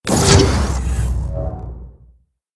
menu-multiplayer-click.ogg